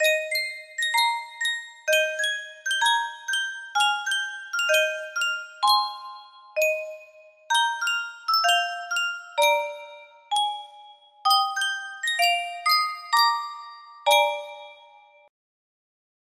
Ma Baby 6498 music box melody
Full range 60